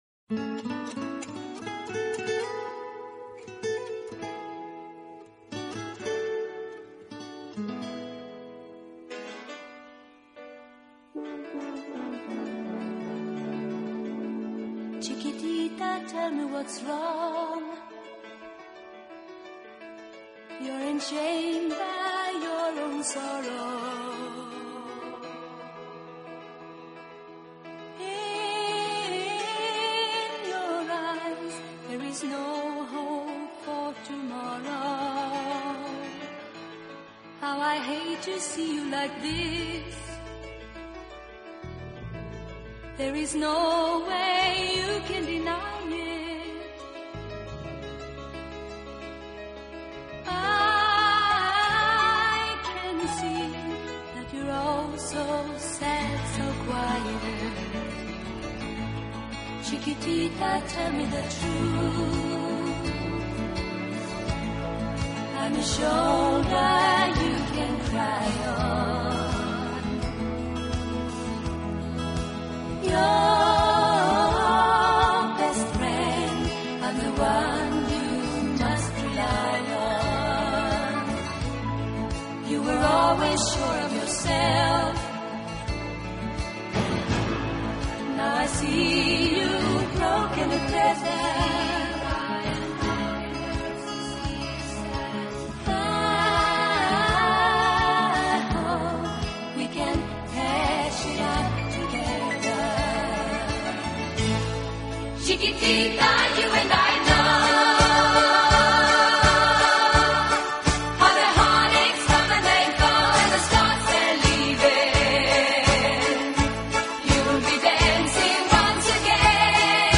音乐风格：流行|流行/摇滚|(Pop/Rock)